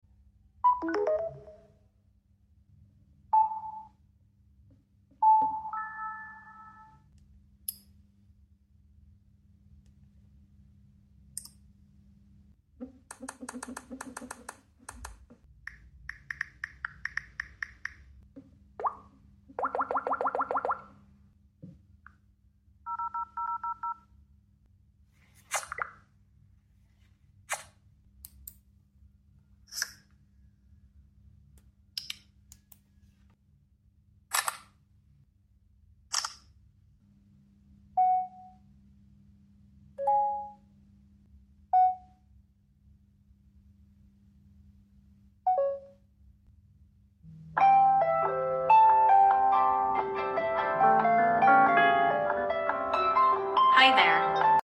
One UI vs TouchWiz UI sound effects free download
One UI vs TouchWiz UI sounds